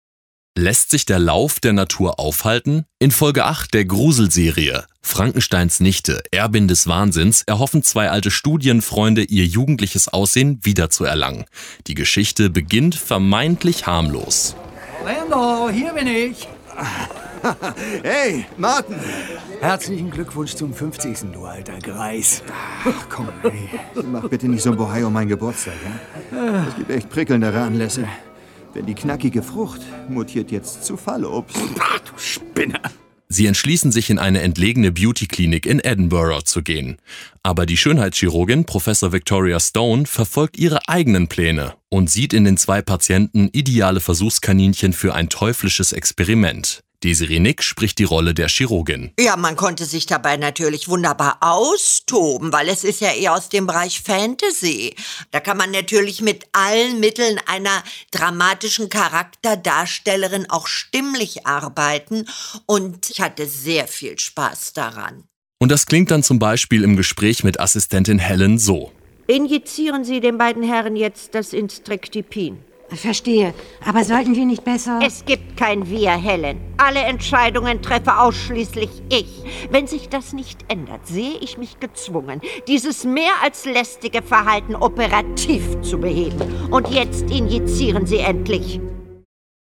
Désirée Nick leiht der perfiden Schönheitschirurgin Victoria Stone ihre Stimme.
Desirée Nick in der Rolle der perfiden Schönheitschirurgin